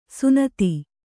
♪ sunati